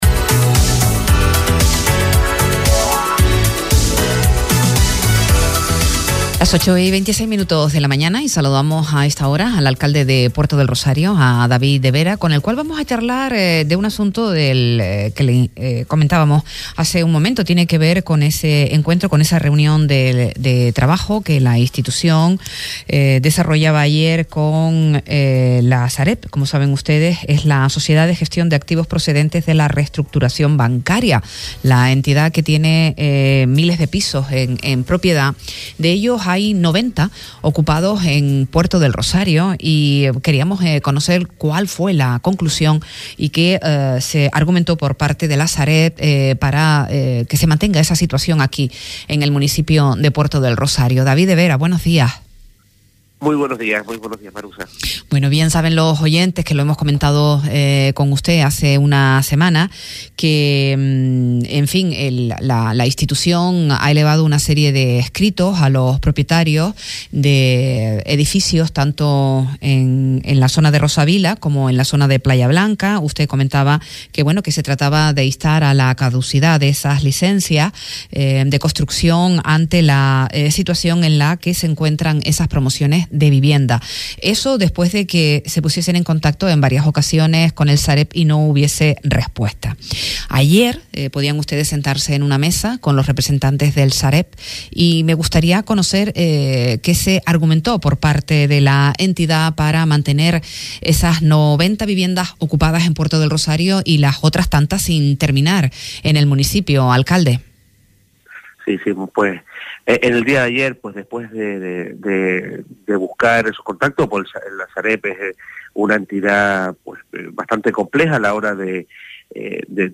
A Primera Hora, entrevista a David de Vera, alcalde de Puerto del Rosario - 08.05.24 - Radio Sintonía
Entrevistas